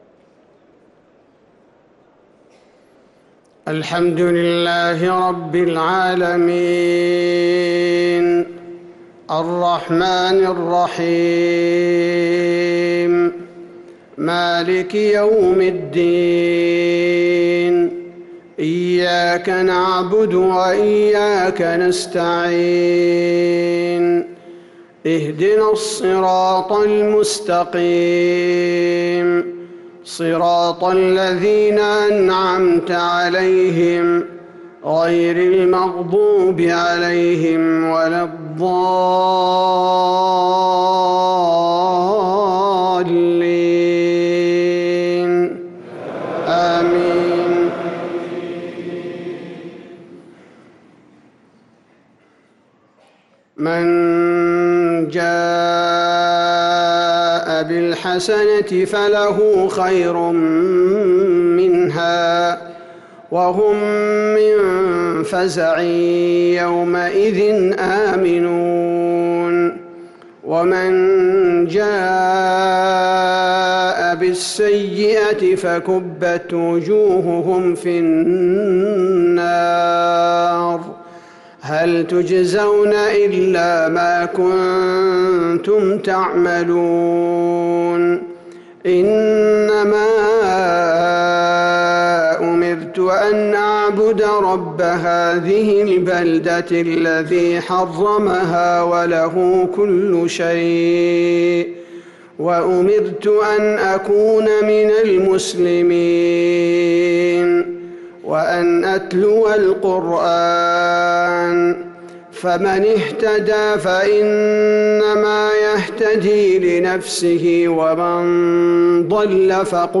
صلاة المغرب للقارئ عبدالباري الثبيتي 16 رجب 1445 هـ